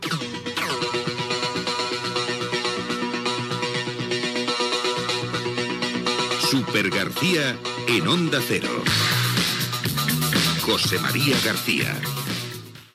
Careta d'entrada del programa